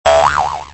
bounce.mp3